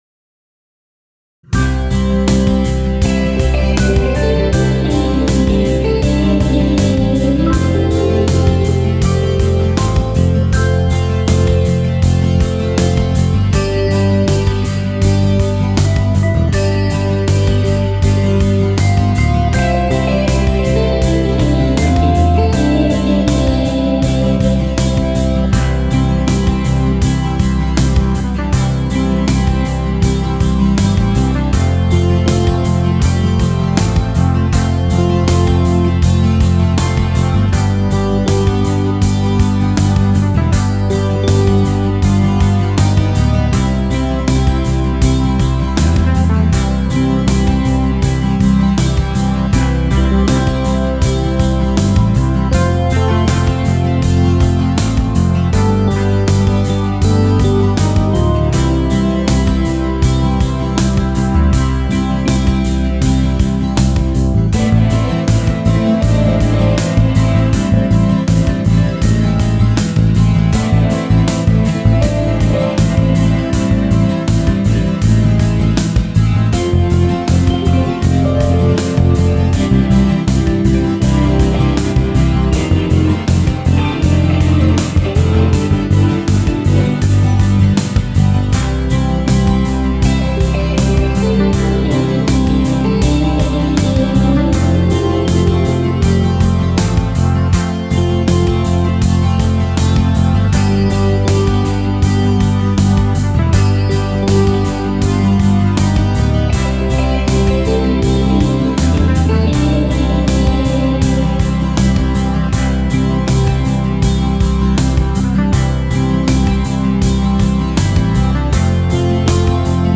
Инструментальная пьеса
• Слова: Инструментал